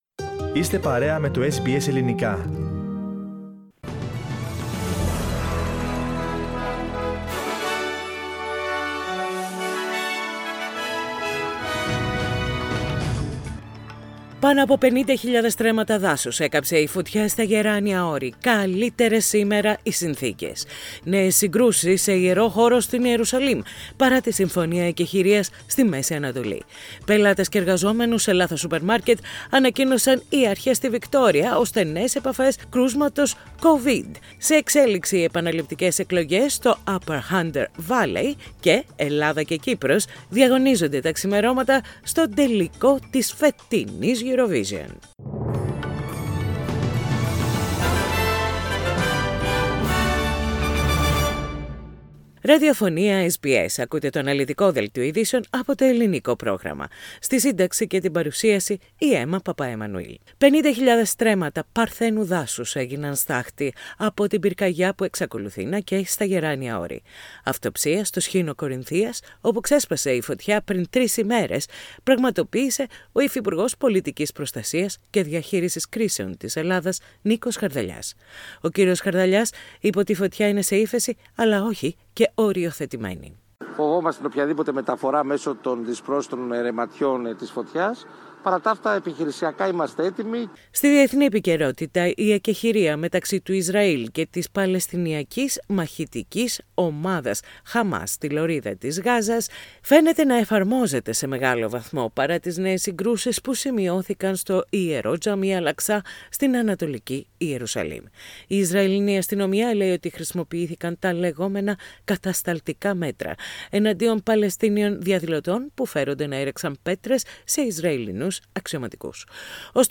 Οι κυριότερες ειδήσεις της ημέρας από το Ελληνικό πρόγραμμα της ραδιοφωνίας SBS.